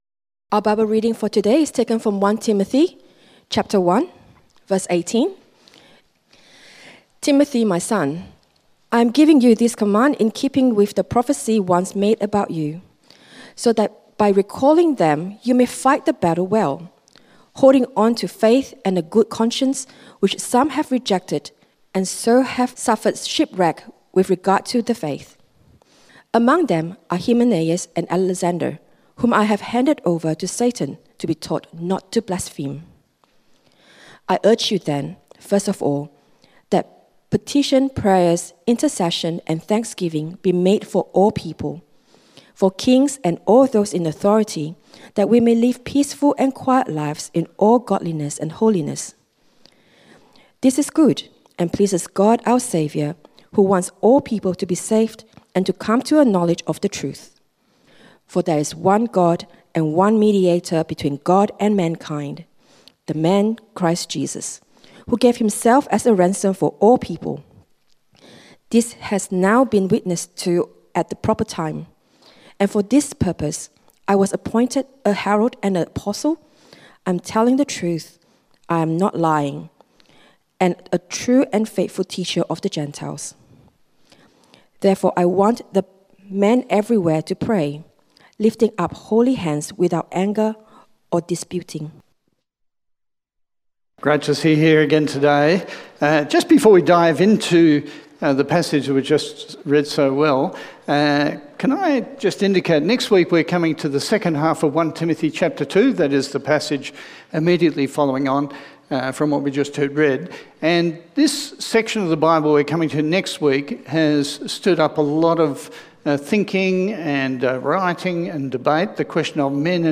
1 Timothy Sermon outline